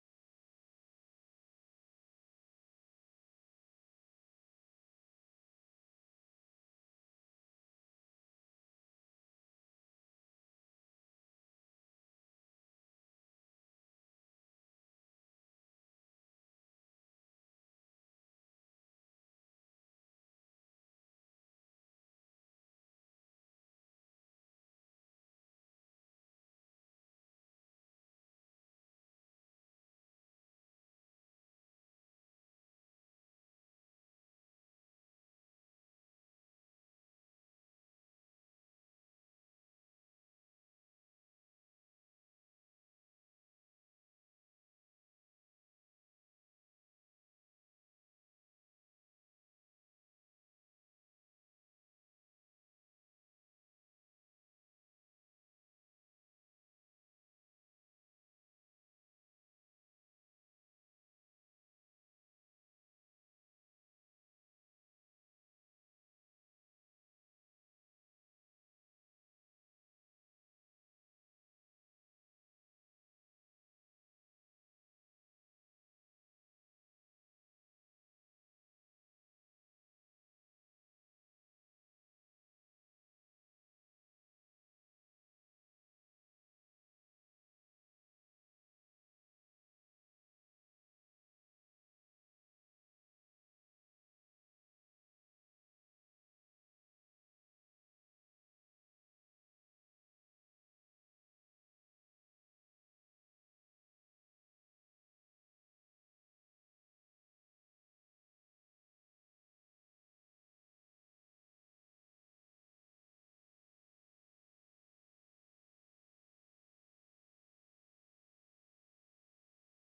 SATB Choir and Piano
Hymn arrangement